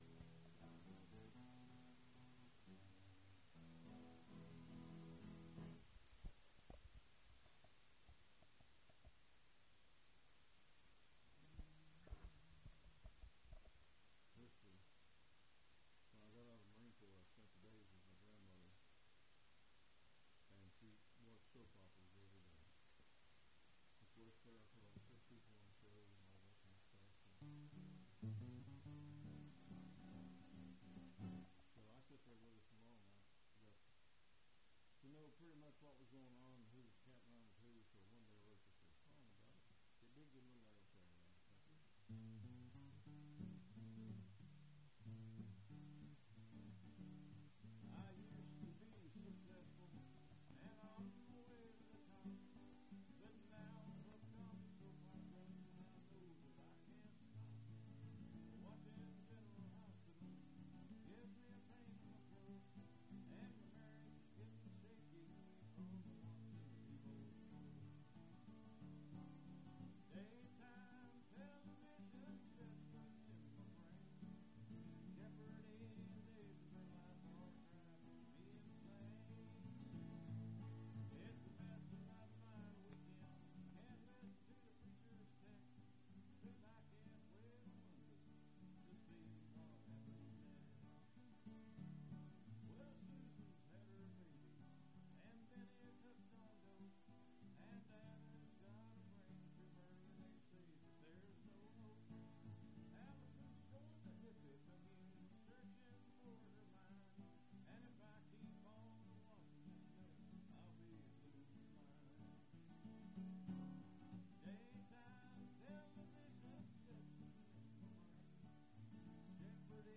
plays and narrates three of his original songs
Folk music--West Virginia
Country music Rights